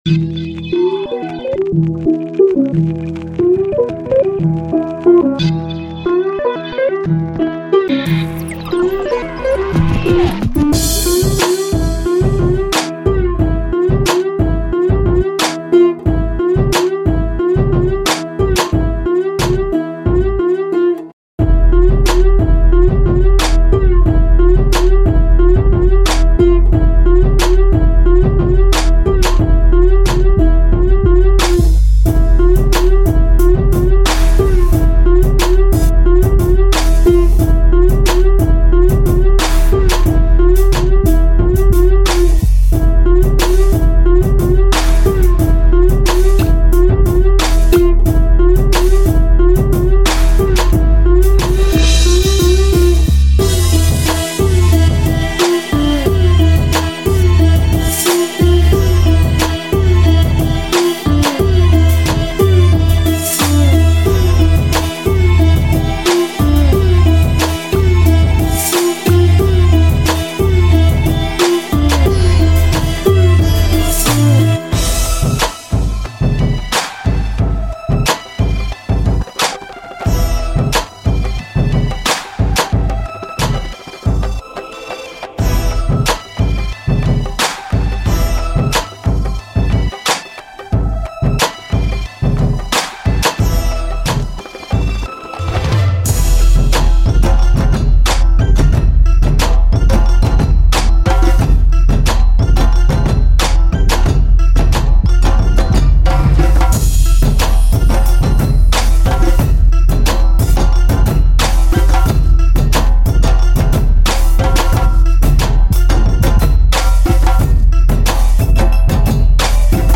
دانلود بیت رپ
موزیک بی کلام